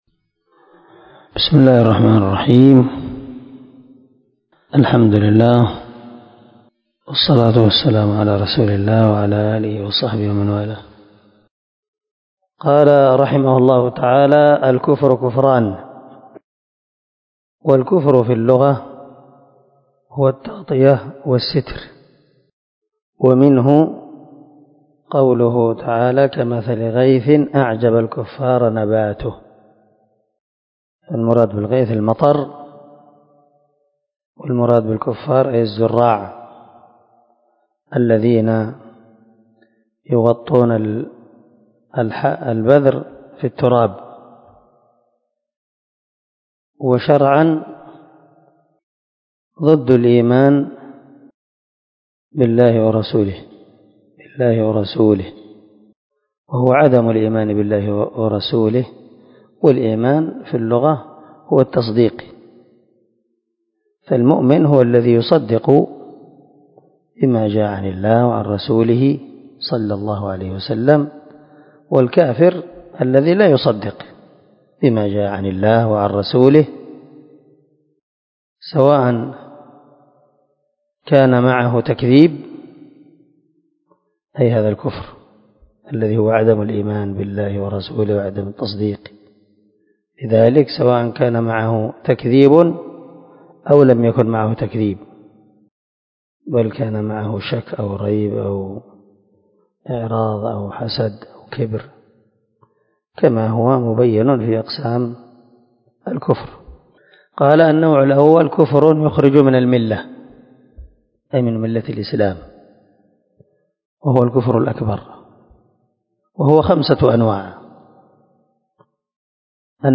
🔊الدرس 31 النوع الأول من أنواع الكفر الذي يخرج من الملة كفر التكذيب